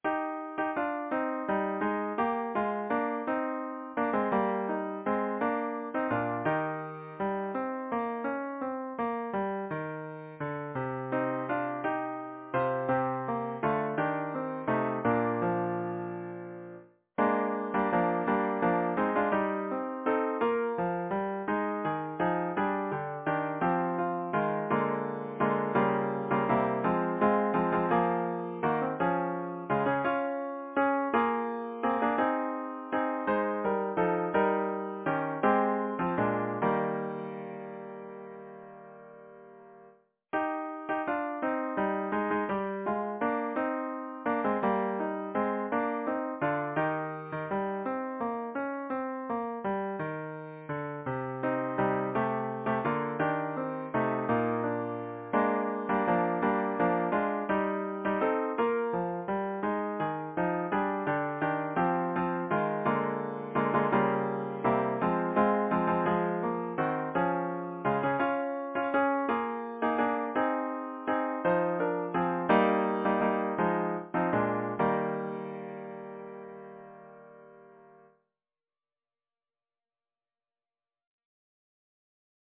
Title: Chillingham Composer: Charles Villiers Stanford Lyricist: Mary E. Coleridge Number of voices: 4vv Voicing: SATB Genre: Secular, Partsong
Language: English Instruments: A cappella